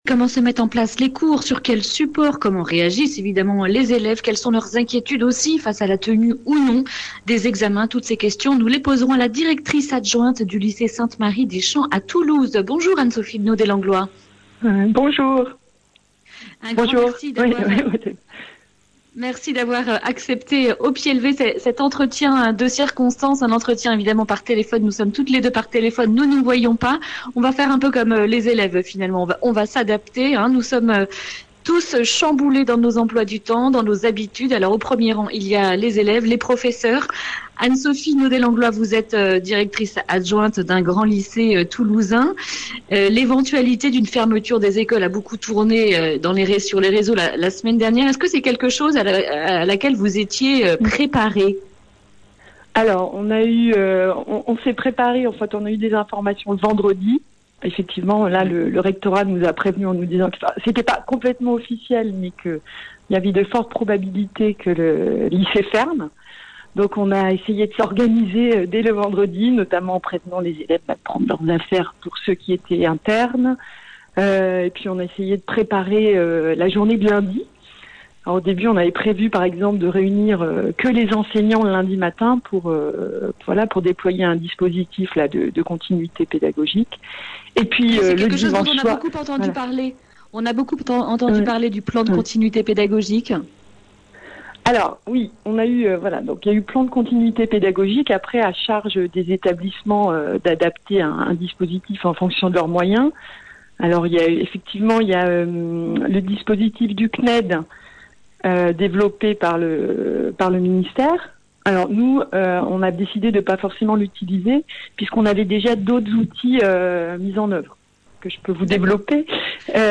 Accueil \ Emissions \ Information \ Régionale \ Le grand entretien \ Comment se mettent en place les cours à distance ?